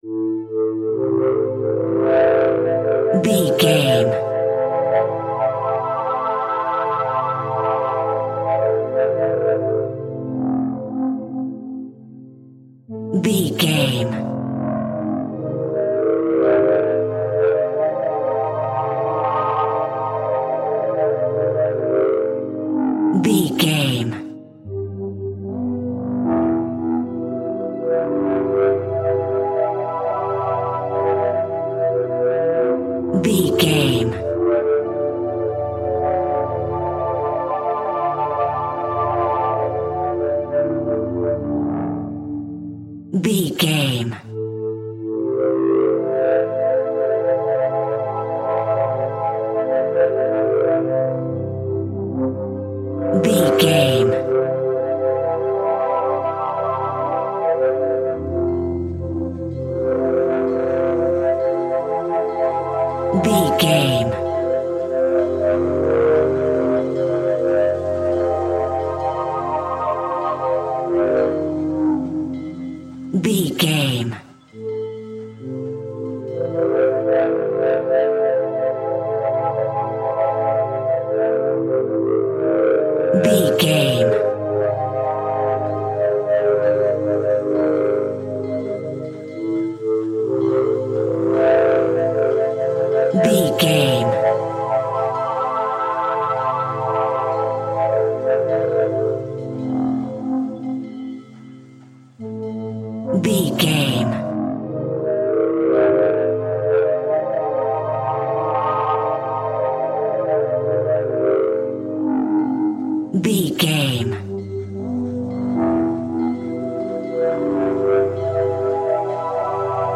Aeolian/Minor
SEAMLESS LOOPING?
Slow
ominous
eerie
synthesiser
horror music
Horror Pads
Horror Synths